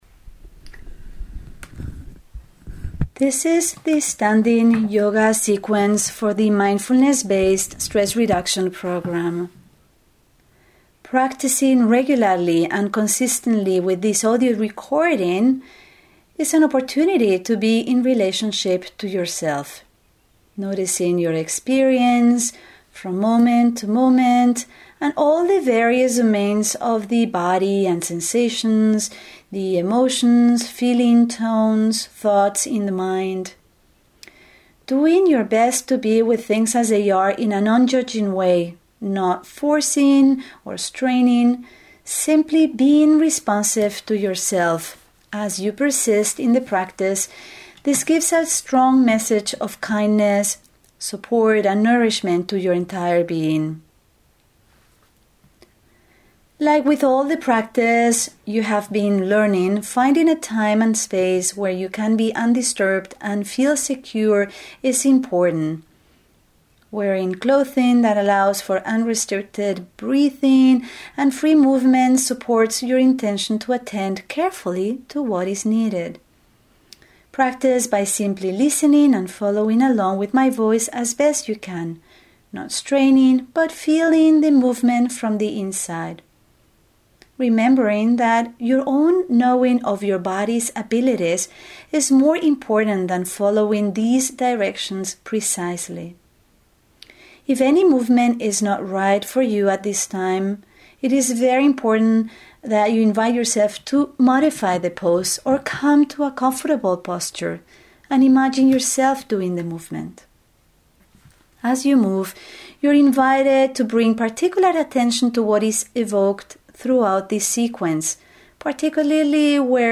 45-минутное занятие йогой в положении стоя:
MMA-Standing Yoga-45mins.MP3